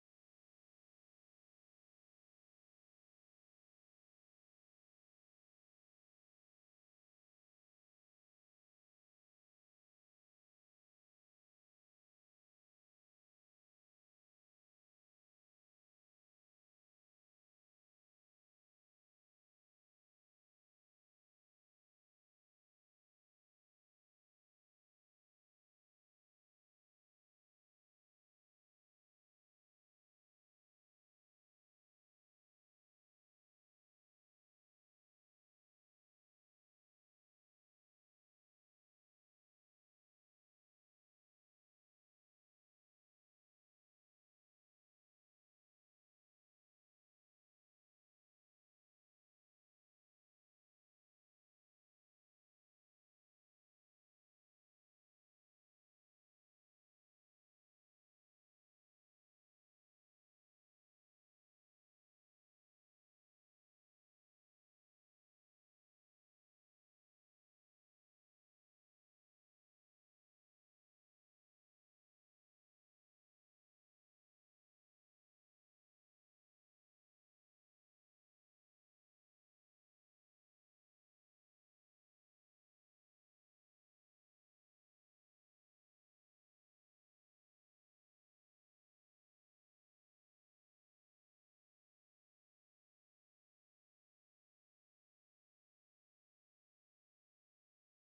Kindertänze: Ziehet durch die goldne Brücke
Tonart: C-Dur, B-Dur
Taktart: 4/8, 3/4
Tonumfang: Oktave
Besetzung: vokal